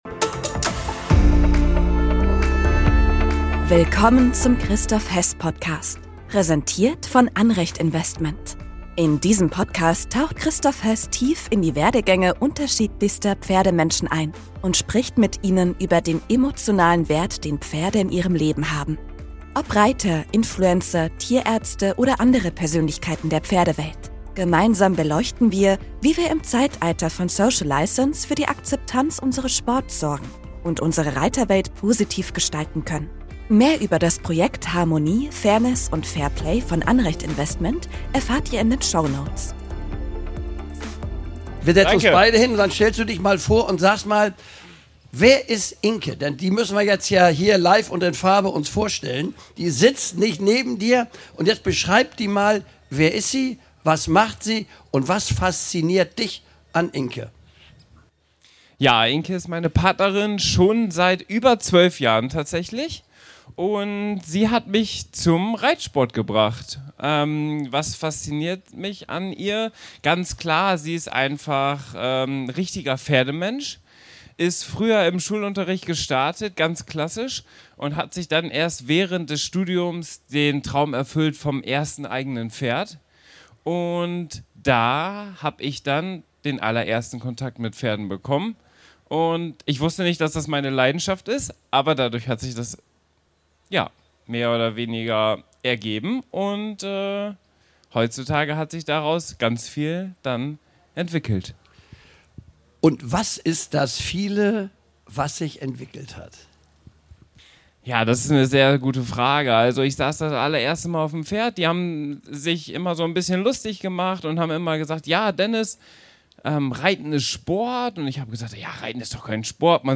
Diese Folge wurde als Live-Podcast bei den Bundeschampionaten in Warendorf im September 2024 aufgezeichnet.